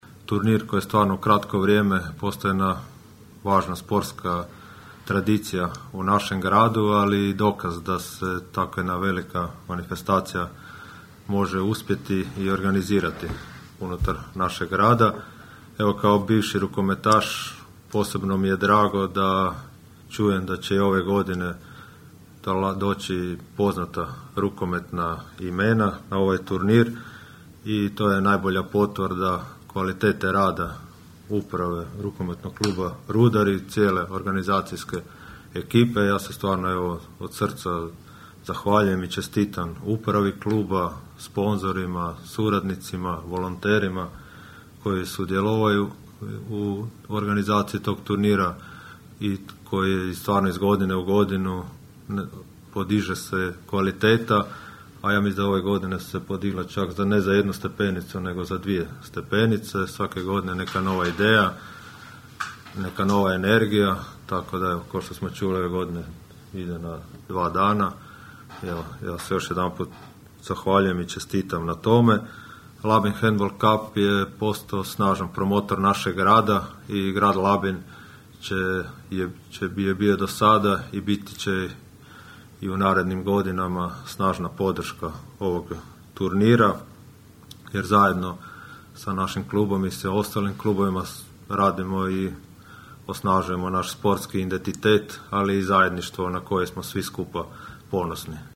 Na današnjoj konferenciji za novinare predstavljen je četvrti međunarodni rukometni turnir Labin Handball Cup, koji će se održati 30. i 31. siječnja iduće godine.
Snažan partner turnira ostaje i Grad Labin, istaknuo je gradonačelnik Donald Blašković: (